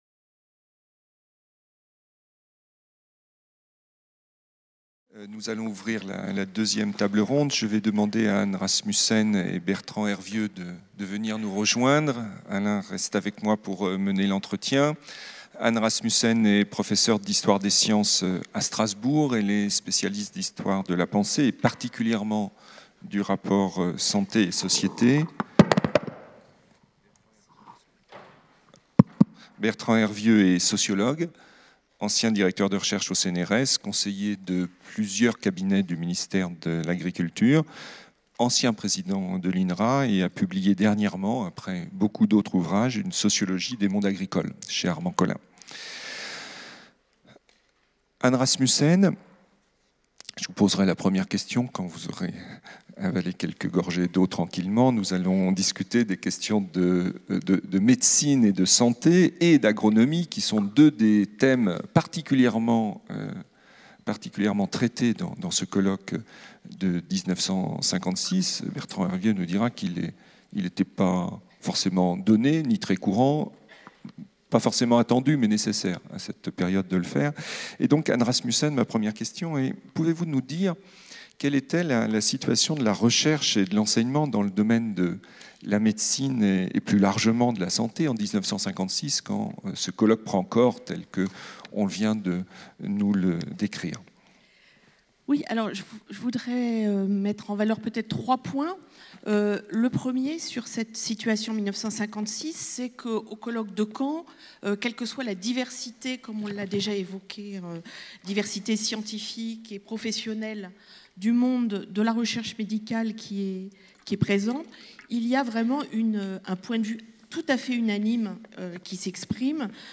C’est le sens de la journée nationale qui s'est tenue à Caen le 3 novembre 2016 - journée qui s'est conclue par une allocution du président de la République.